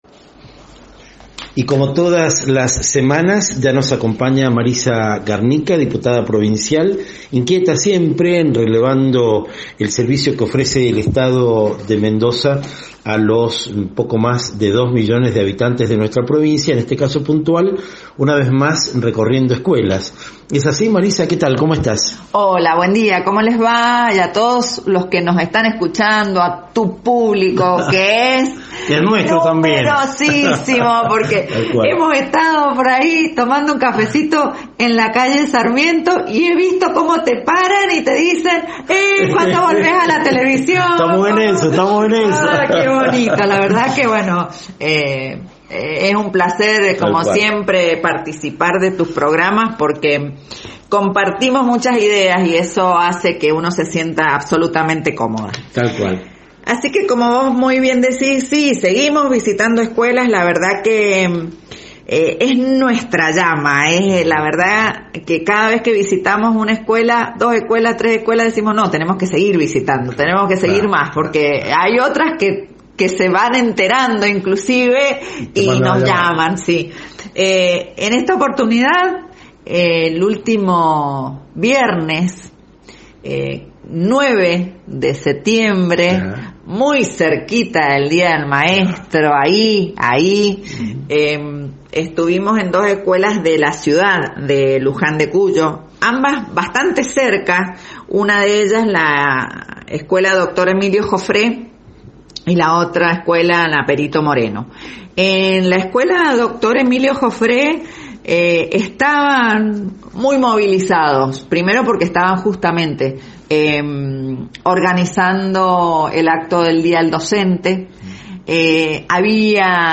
Entrevista: Marisa Garnica, Diputada Provincial